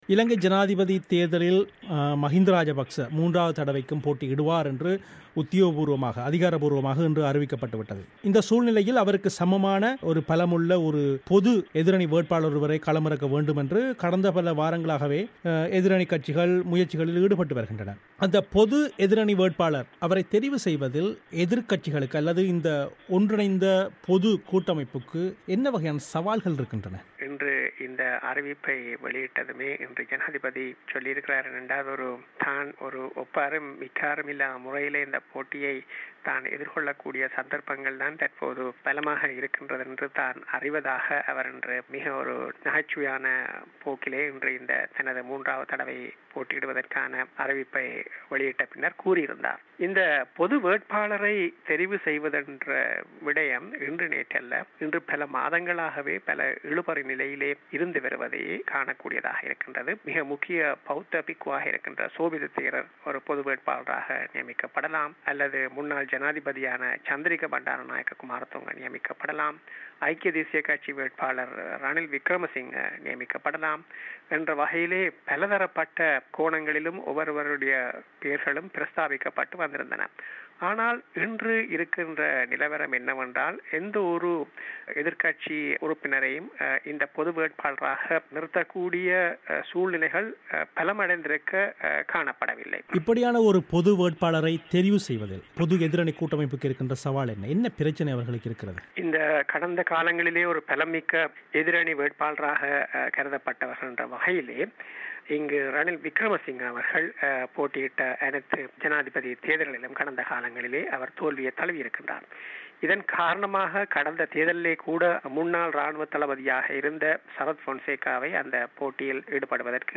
பிபிசி தமிழோசைக்கு அளித்த செவ்வி.